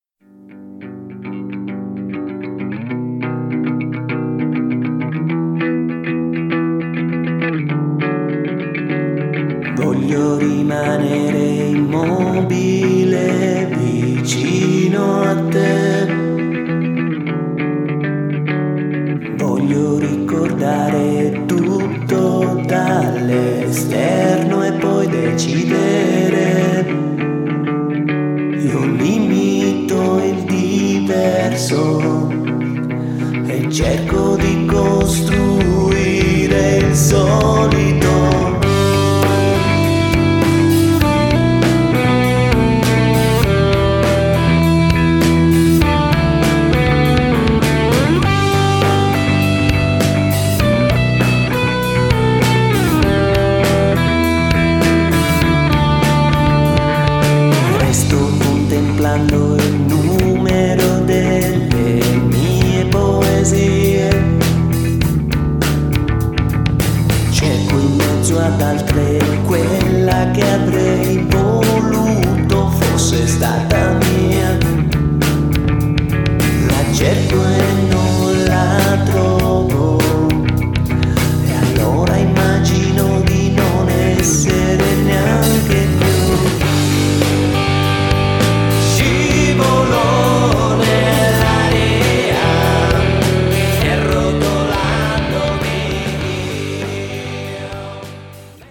Genere: Rock.